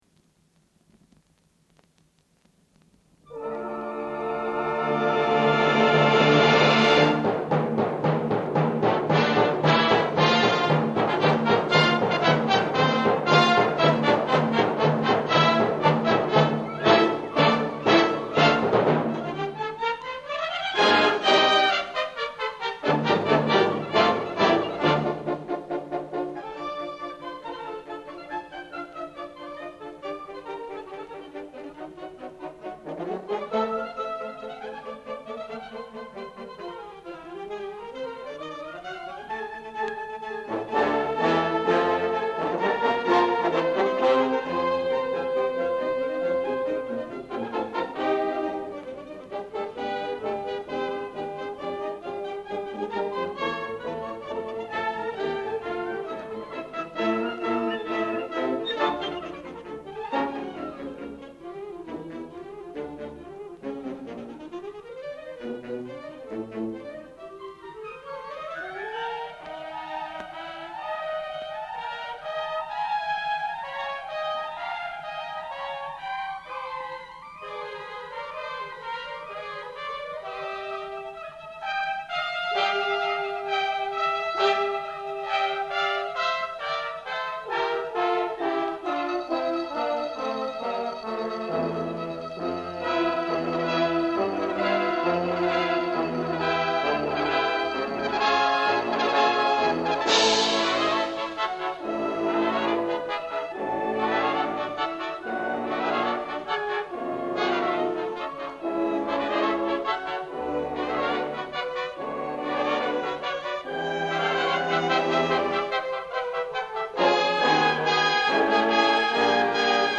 第20回 群馬県吹奏楽コンクール
会場：群馬音楽センター